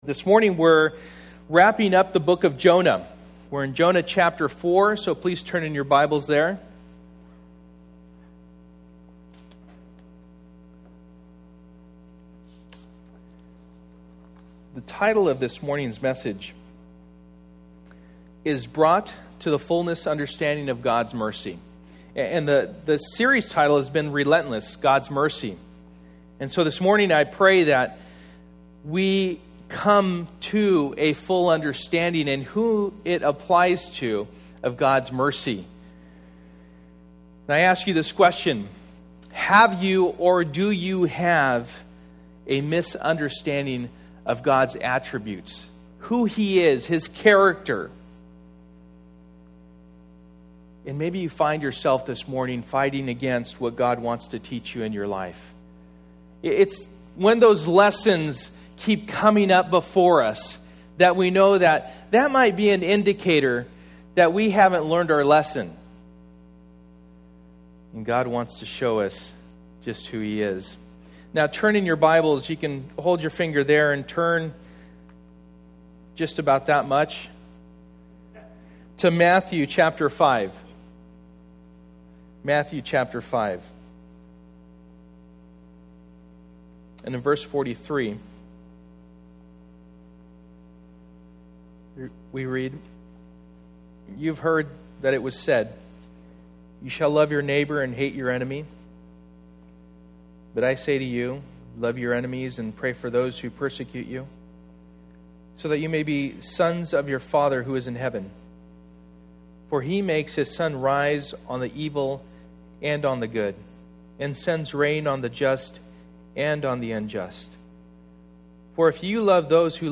Jonah Passage: Jonah 4:1-11 Service: Sunday Morning %todo_render% « Second Chances From Anger to Hate